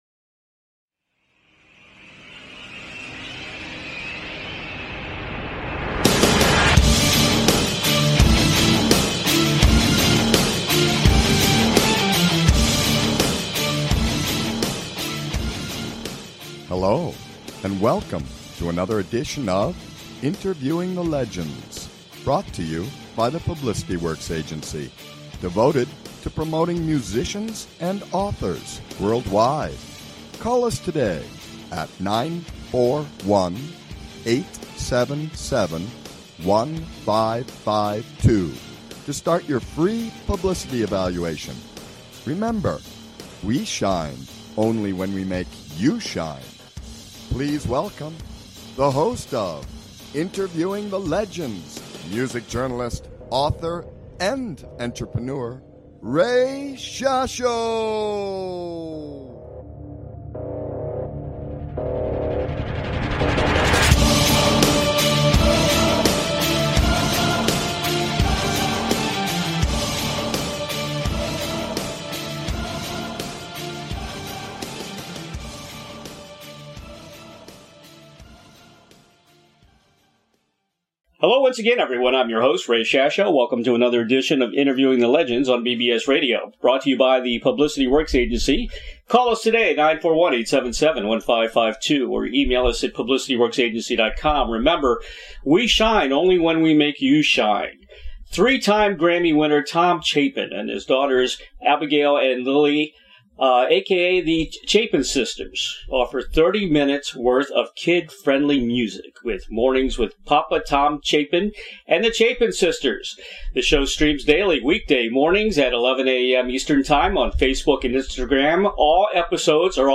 Guest, Tom Chapin